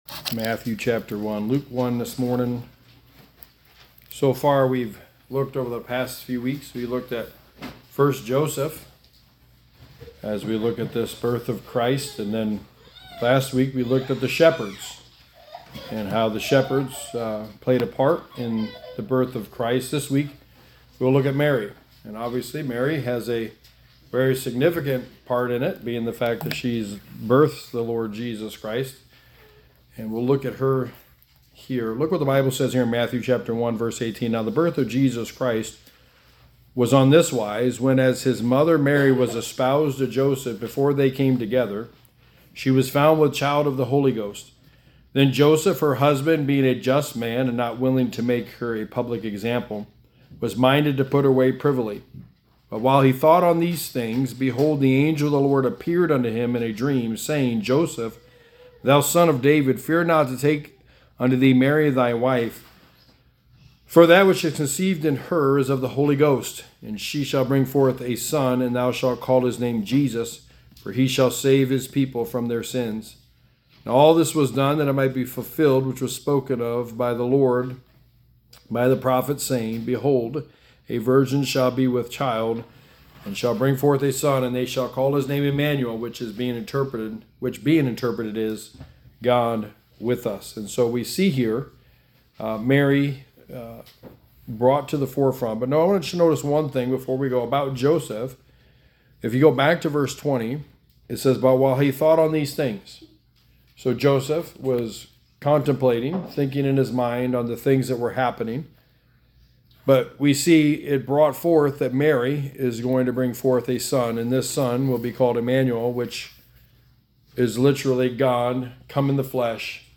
Sermon 3: The Birth of Christ – Mary
Service Type: Sunday Morning